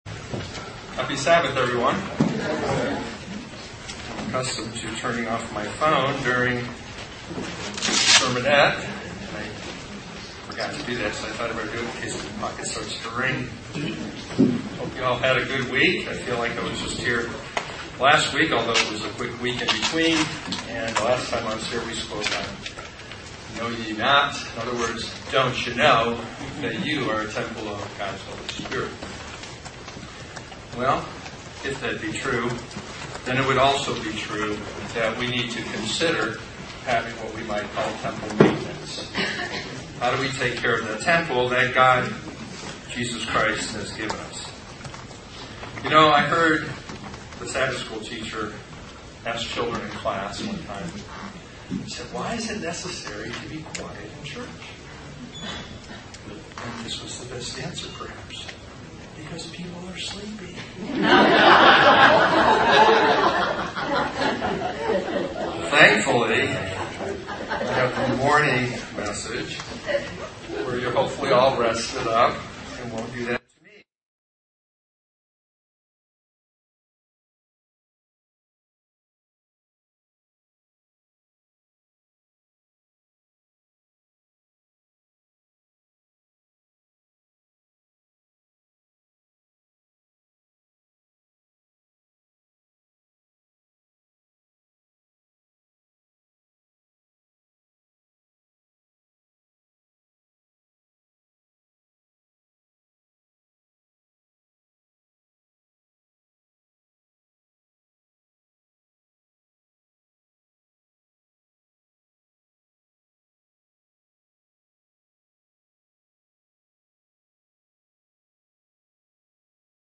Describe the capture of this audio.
Given in St. Petersburg, FL